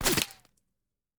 5caee9fba5 Divergent / mods / JSRS Sound Mod / gamedata / sounds / material / bullet / collide / dirt02gr.ogg 36 KiB (Stored with Git LFS) Raw History
dirt02gr.ogg